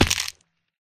fallbig1.ogg